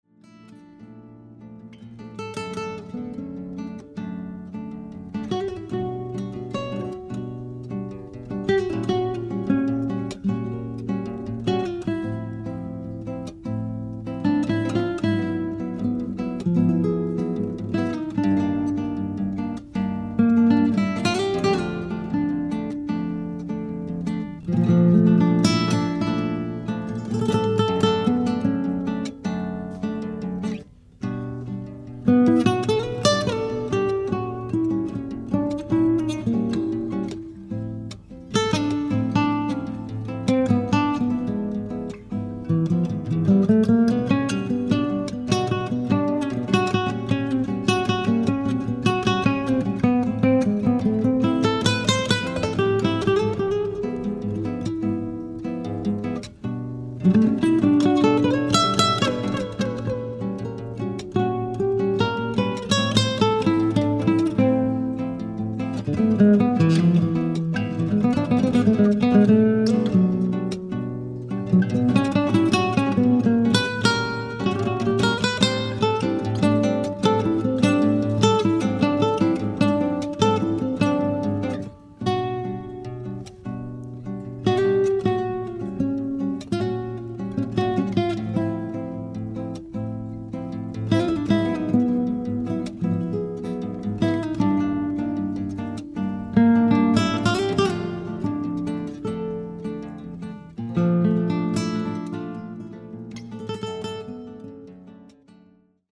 Spanish